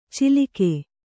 ♪ cilike